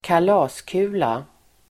Uttal: [²kal'a:sku:la]